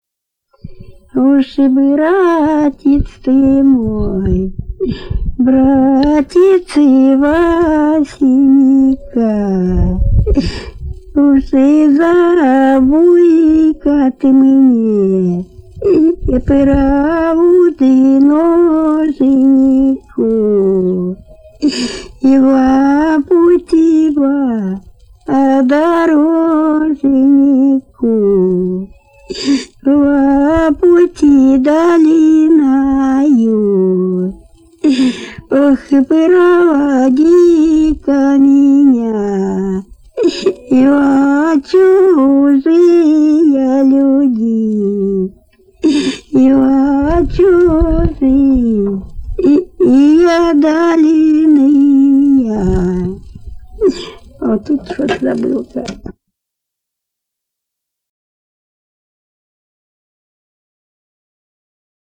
Народные песни Касимовского района Рязанской области «Уж и братец ты мой», плач невесты.
05_-_05_Уж_и_братец_ты_мой,_плач_невесты.mp3